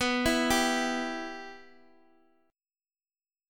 Listen to Bsus4#5 strummed